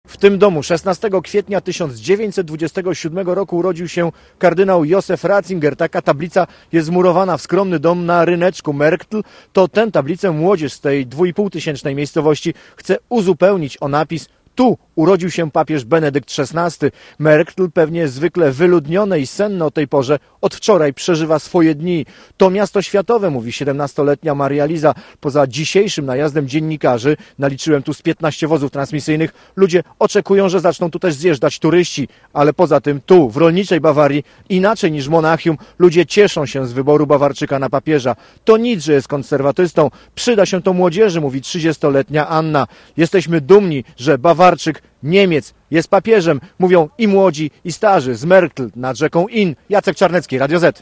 W ciągu jednej doby prowincjonalne miasteczko Marktl am Inn, gdzie urodził się Joseph Ratzinger, stało się niemalże centrum świata. Przed rodzinnym domem nowego papieża był specjalny wysłannik Radia ZET.